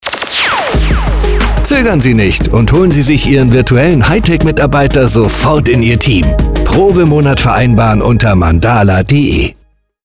mandala on air bei radio38